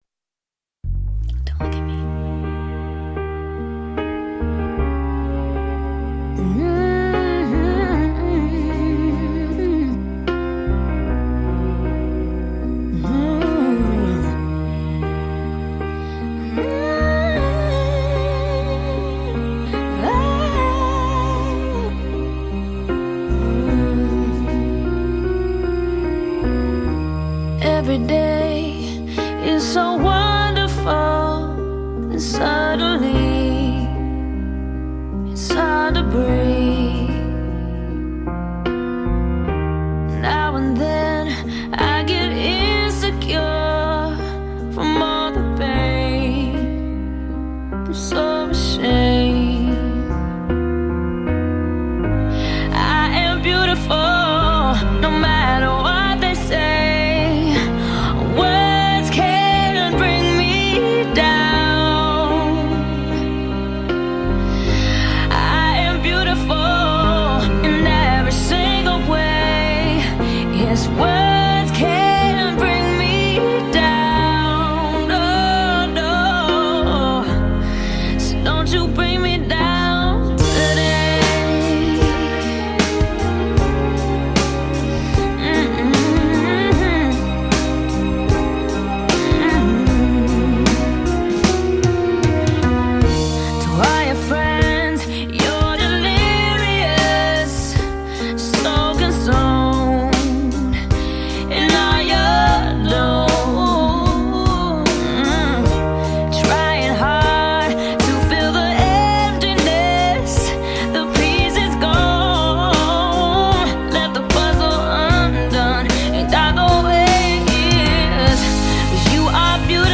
popowa piosenka